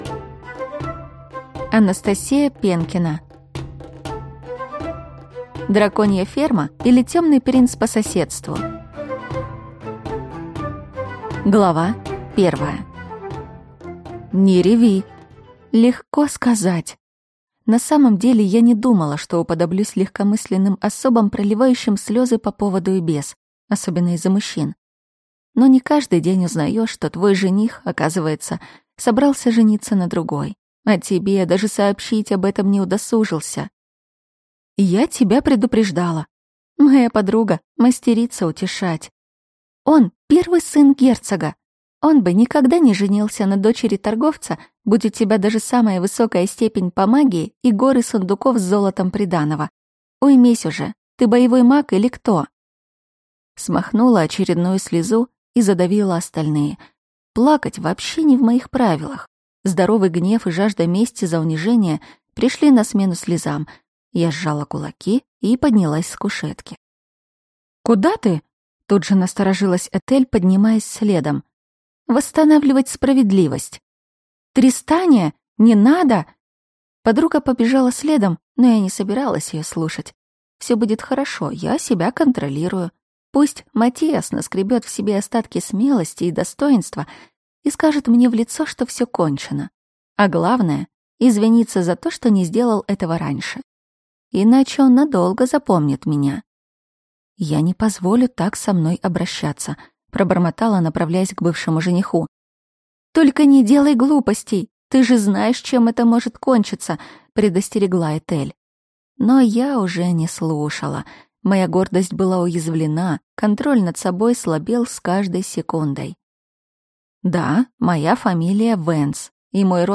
Аудиокнига Драконья ферма или темный принц по соседству | Библиотека аудиокниг